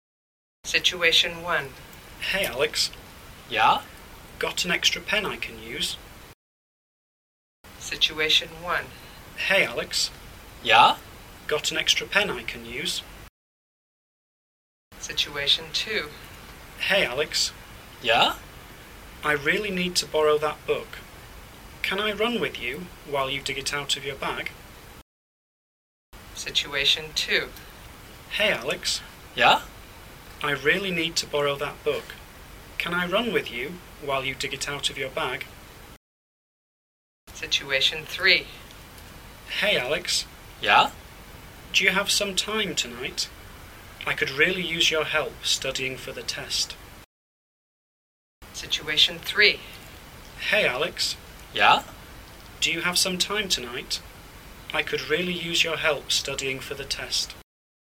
Ch4 " Follow-up Activity 4 - Slow - Repeat.mp3
Ch4-Follow-upActivity-Slow-Repeat.mp3